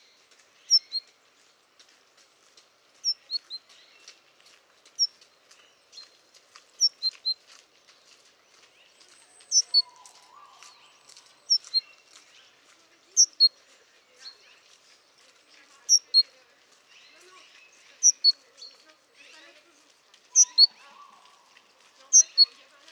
Parus major major
field recording